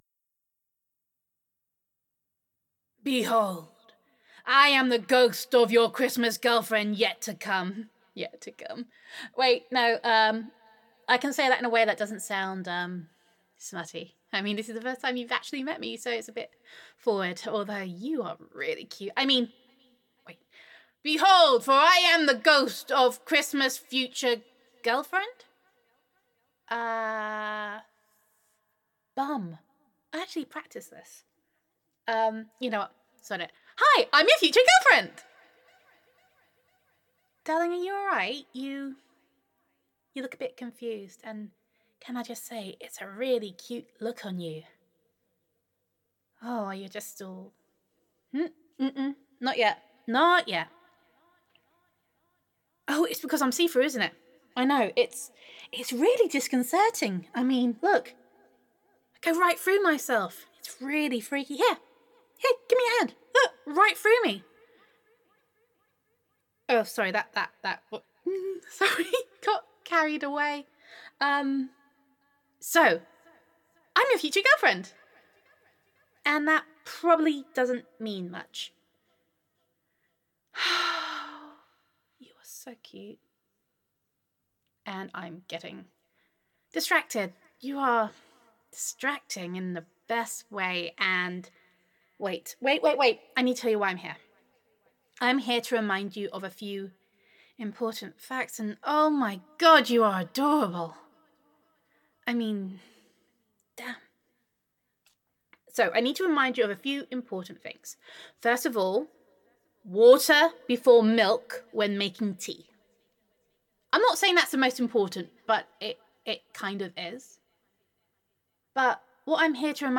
Comforting Christmas Ghost Roleplay
[F4A] The Christmas Ghost of Your Future Girlfriend [You Are so Distracting][the Most Muppety Ghost][Adoration][Comfort and Reassurance][Don’t Give up Hope][Gender Neutral][Comforting Christmas Ghost Roleplay]